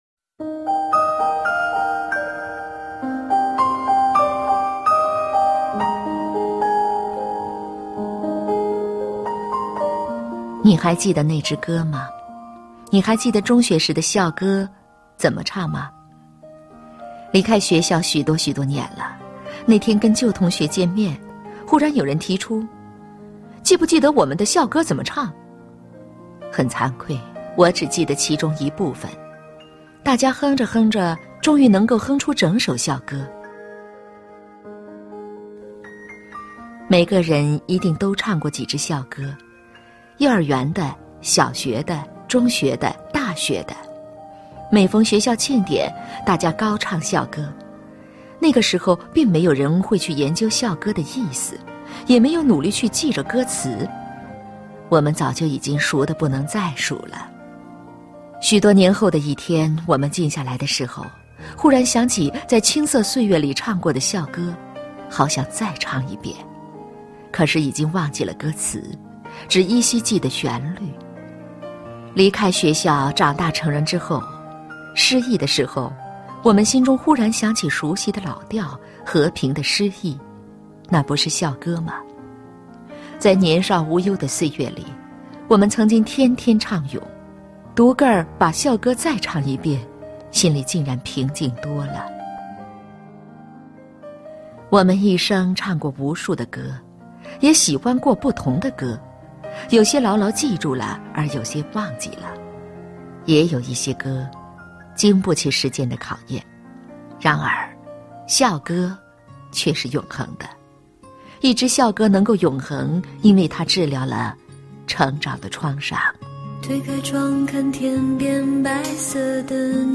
经典朗诵欣赏 张小娴：爱，从来就是一件千回百转的事 目录